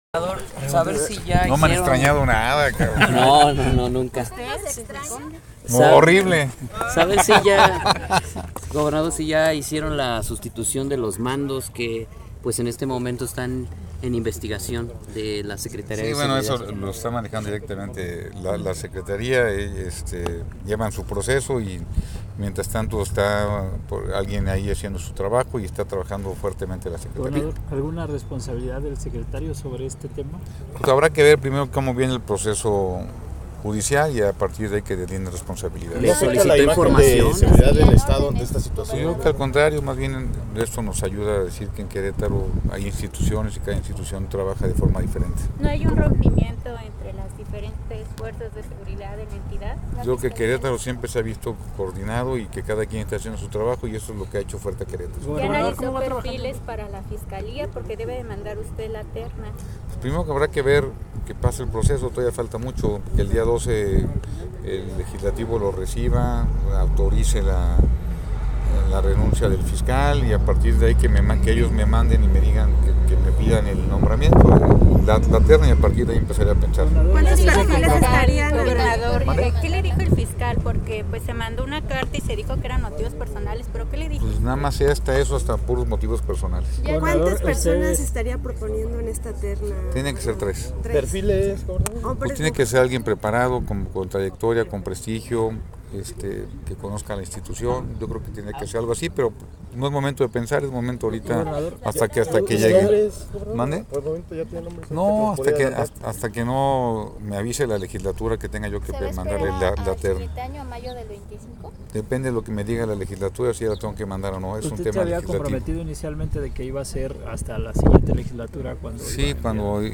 Rueda de prensa Gobernador Mauricio Kuri